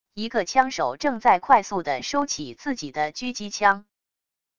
一个枪手正在快速地收起自己的狙击枪wav音频